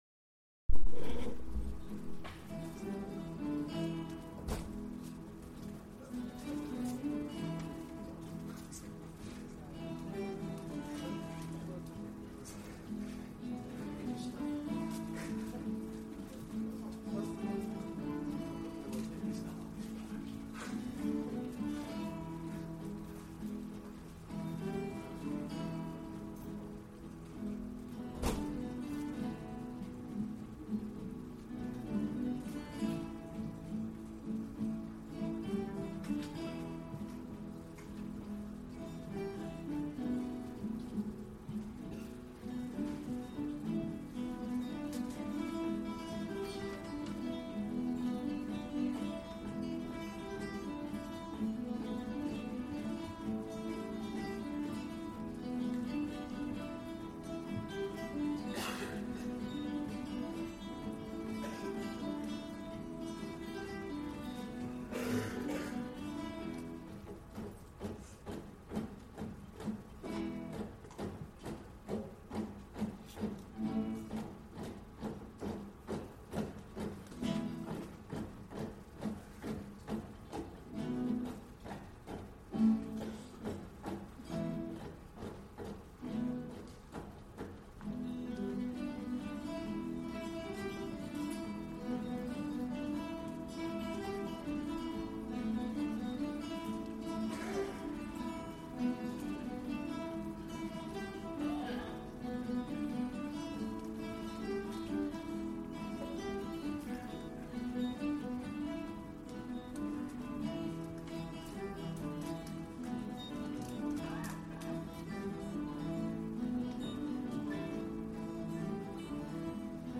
Guitar Ensembles
Summer Concert 2014